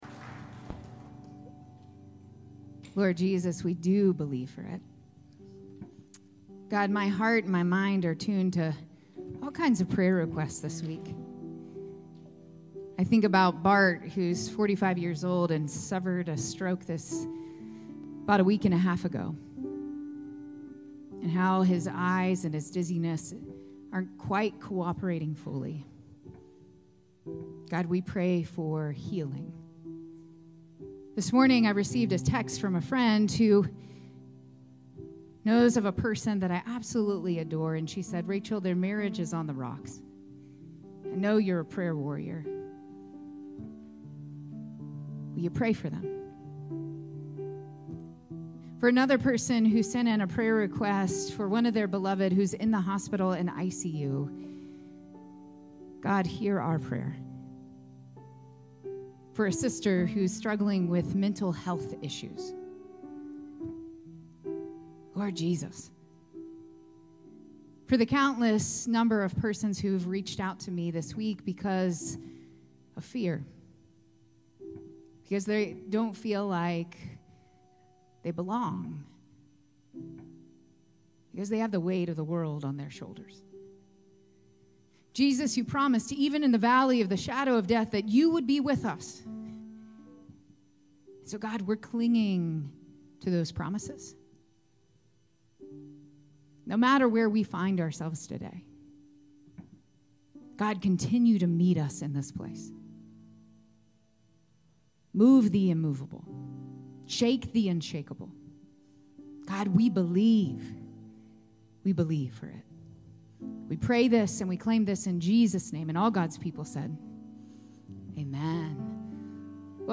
9:30 AM Redemption Worship Service 01/26/25 – New Albany United Methodist Church